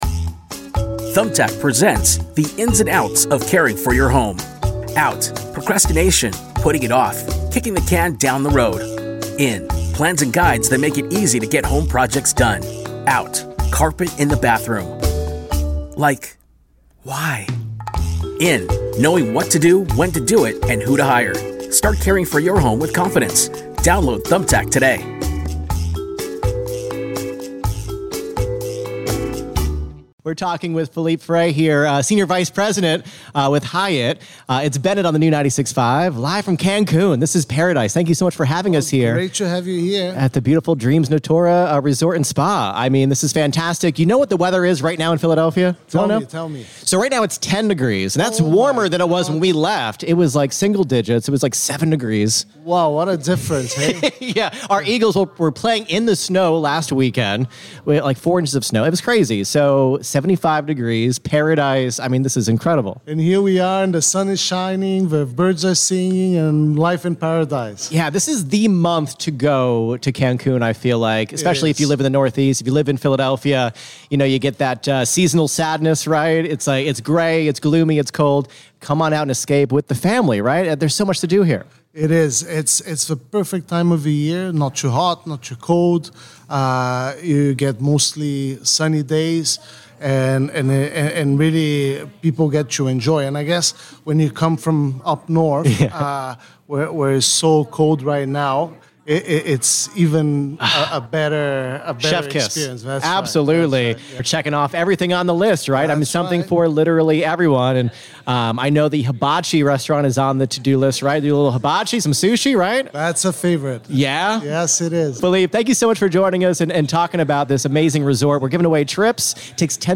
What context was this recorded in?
broadcasting live from Dreams Natura in Cancun, Mexico!